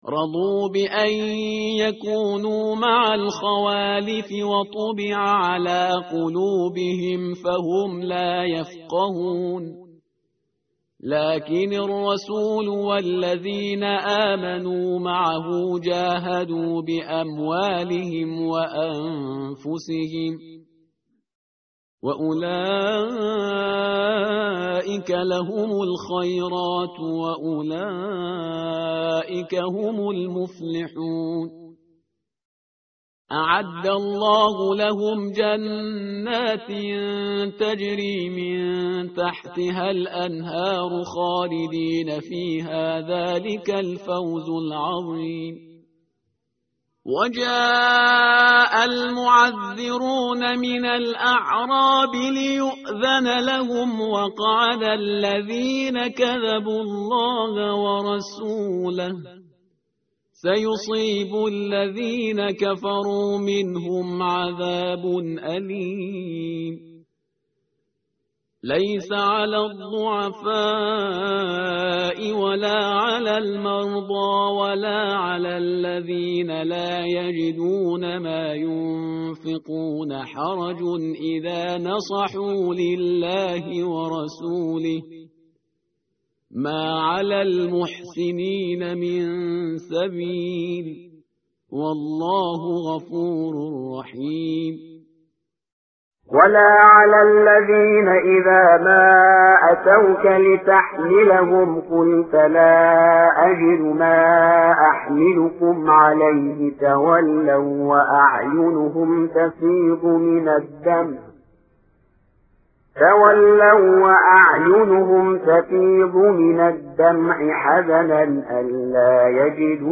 متن قرآن همراه باتلاوت قرآن و ترجمه
tartil_parhizgar_page_201.mp3